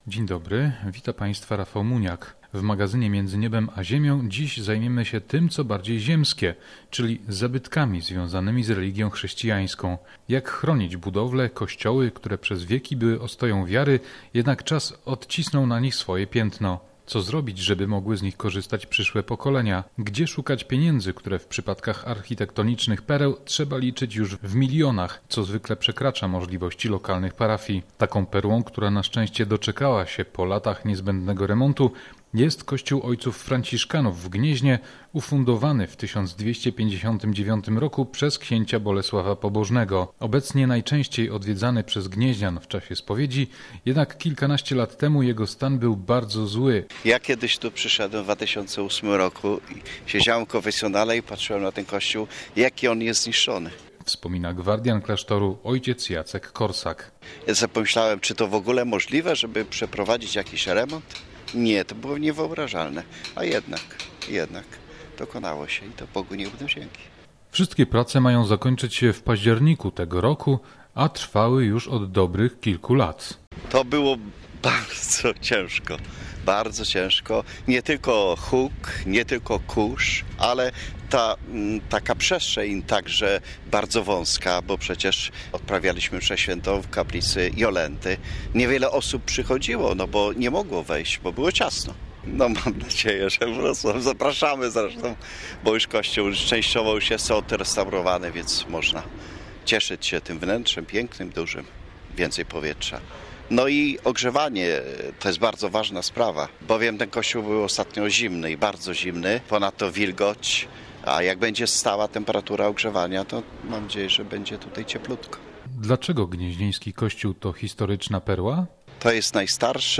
Rozmowa o zabytkach kościelnych.